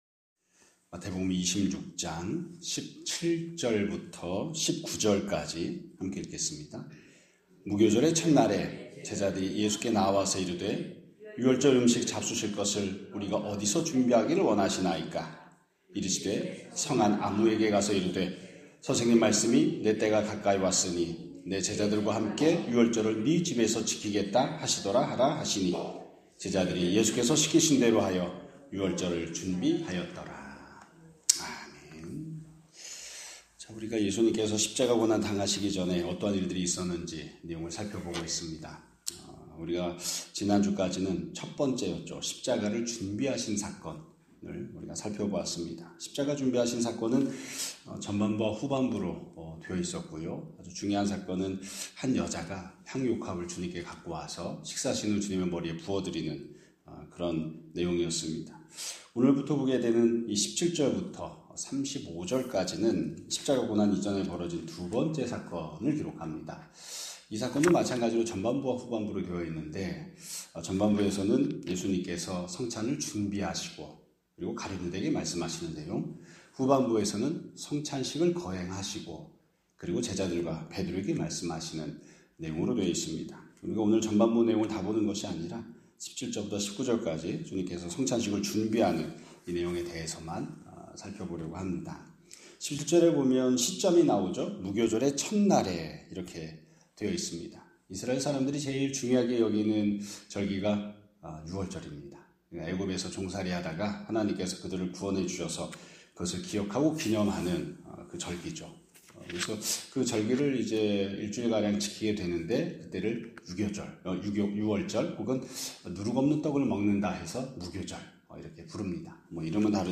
2026년 3월 30일 (월요일) <아침예배> 설교입니다.